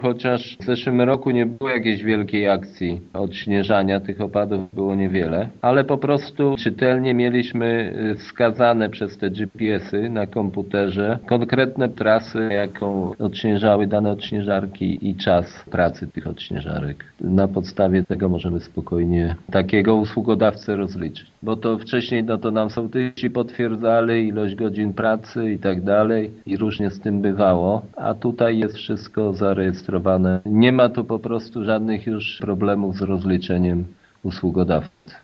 Zdaniem wójta Jacka Anasiewicza przetestowany w ubiegłym sezonie sposób sprawdził się i dlatego samorząd chce kontynuować ten pomysł co najmniej przez kolejne trzy lata: